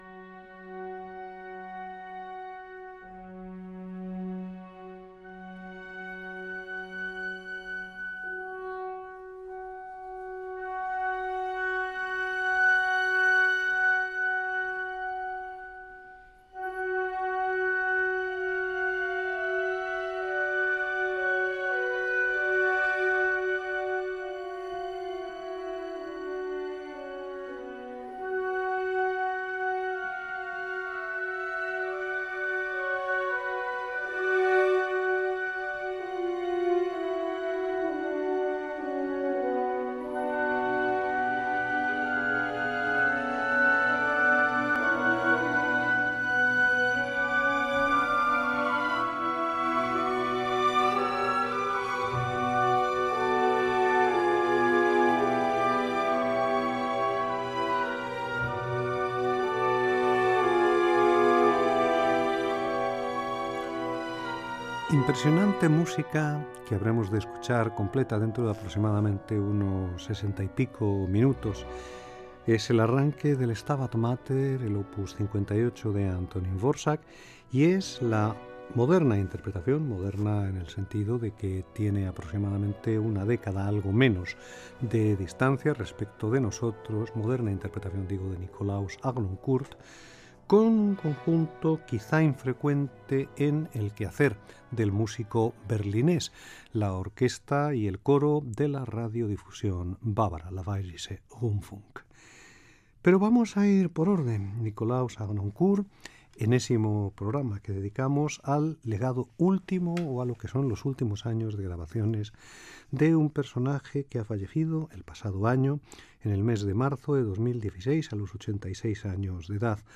Última edició del programa presentada per José Luis Pérez de Arteaga. Tema musical, comentari sobre l'enregistrament que s'ha escoltat i presentació del tema musical que s'escoltarà
Musical